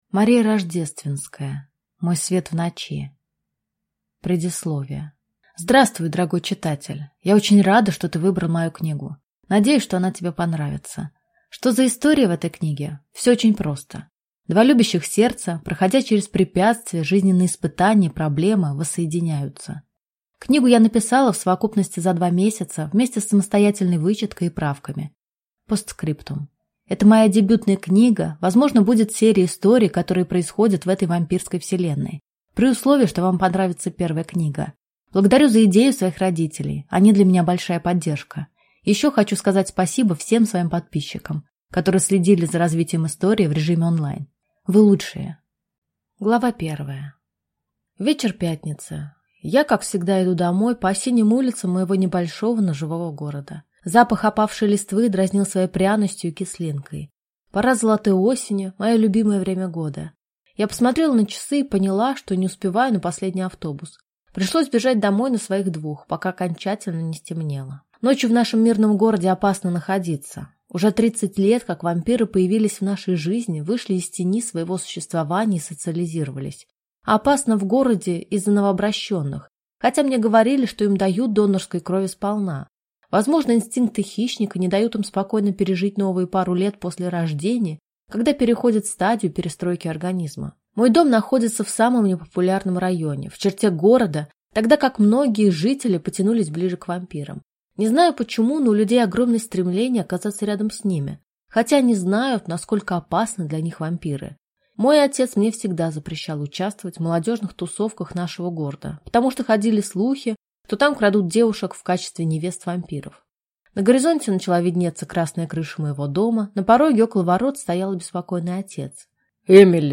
Аудиокнига Мой свет в ночи | Библиотека аудиокниг